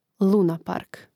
lȕnapark lunapark